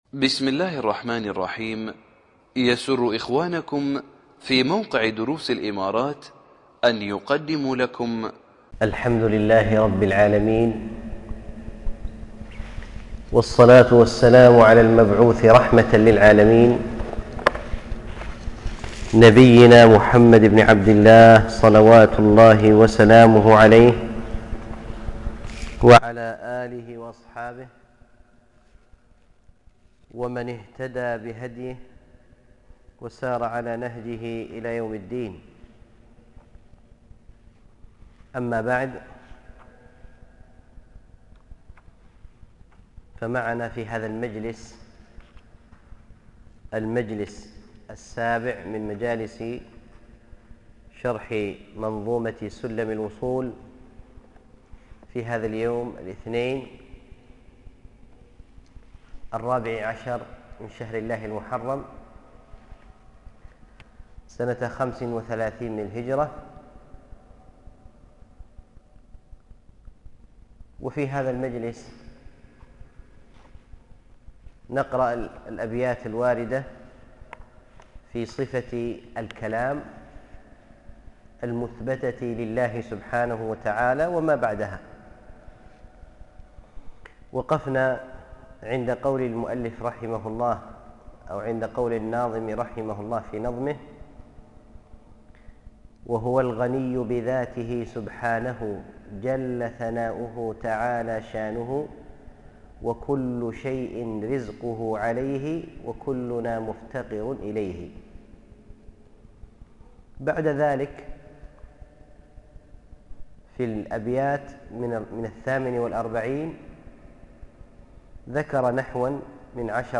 الدرس الحادي والعشرون